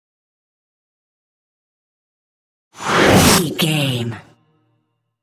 Trailer dramatic raiser short flashback
Sound Effects
Fast paced
In-crescendo
Atonal
intense
tension
riser